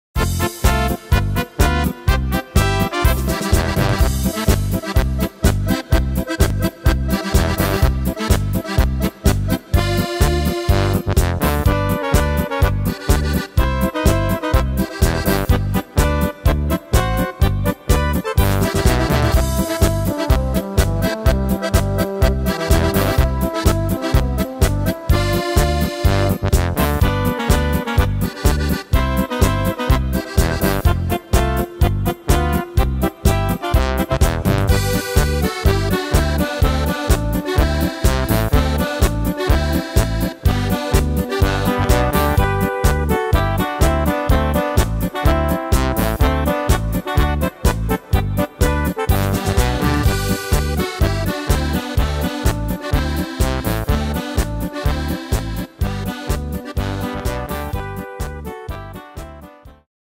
Tempo: 125 / Tonart: C / G / F – Dur